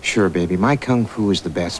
Tom's voice